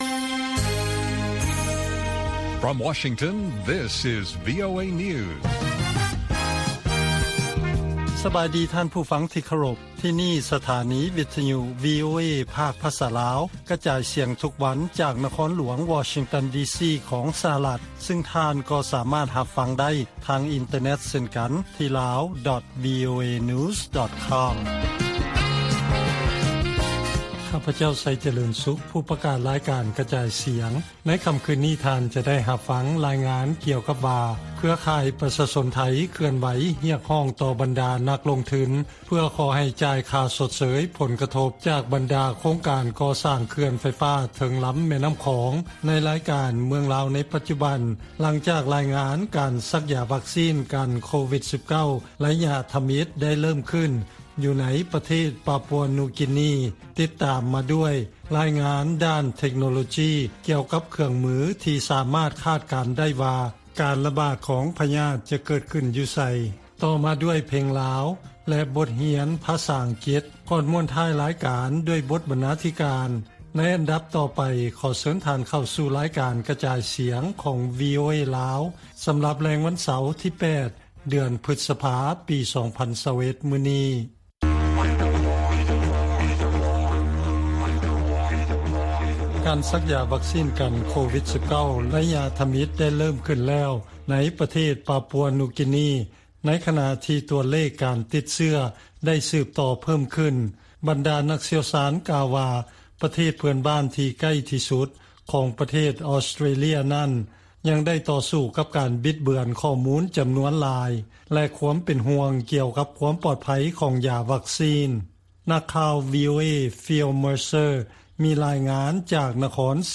ວີໂອເອພາກພາສາລາວ ກະຈາຍສຽງທຸກໆວັນ. ຫົວຂໍ້ຂ່າວສໍາຄັນໃນມື້ນີ້ມີ: 1) ແຜນທີ່ໃຊ້ໃນຄອມພີວເຕີ ສາມາດທໍານາຍໄດ້ວ່າ ຈະມີການລະບາດ ຂອງໂຄວິດ-19 ເກີດຂຶ້ນເມື່ອໃດ.